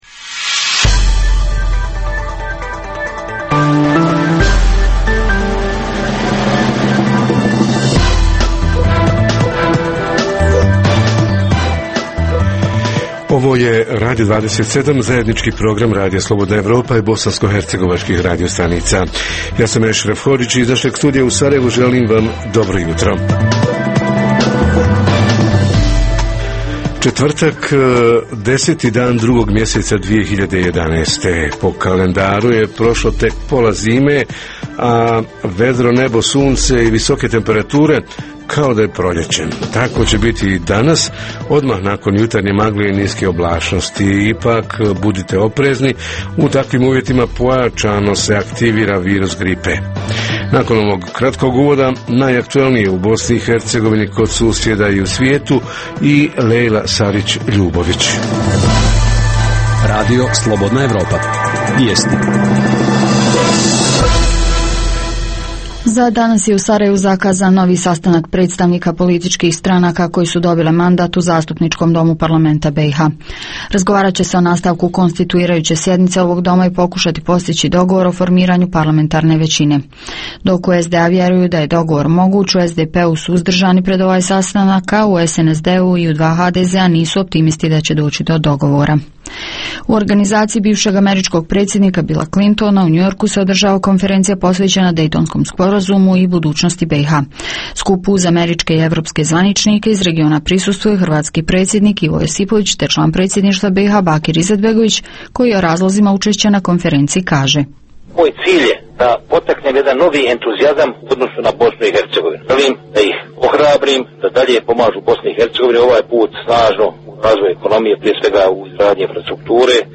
Redovna rubrika Radija 27 četvrtkom je “Radio ordinacija”. Redovni sadržaji jutarnjeg programa za BiH su i vijesti i muzika.